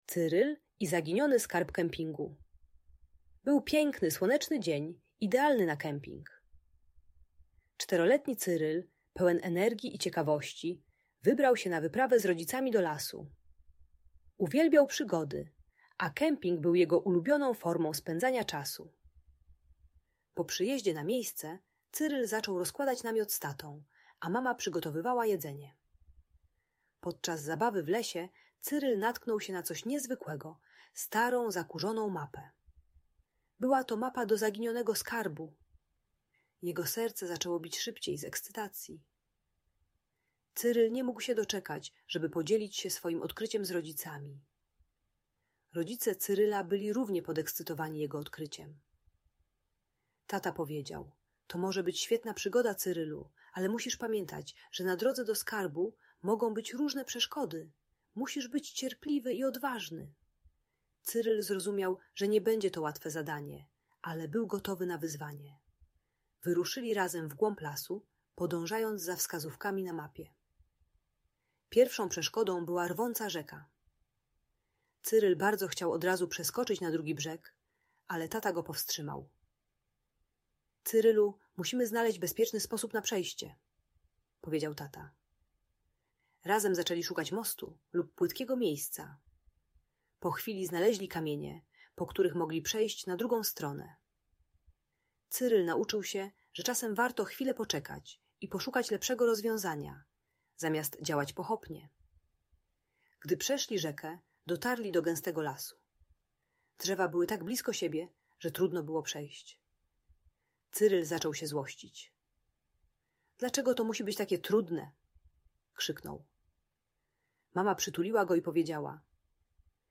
Historia Cyryla i Zaginionego Skarbu Kempingu - Audiobajka